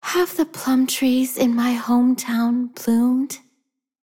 大厅语音